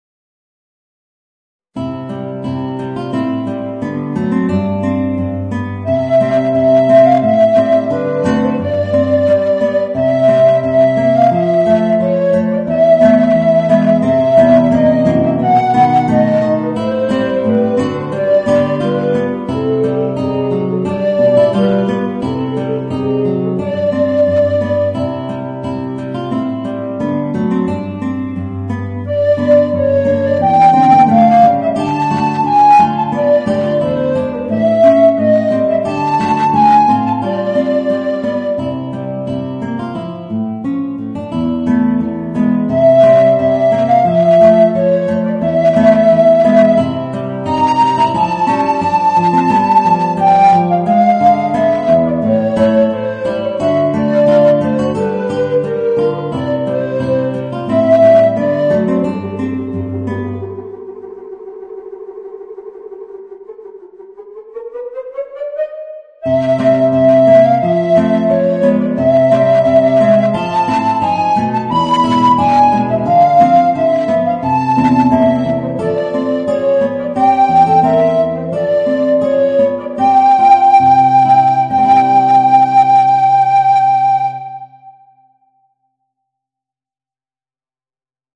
Voicing: Alto Recorder and Guitar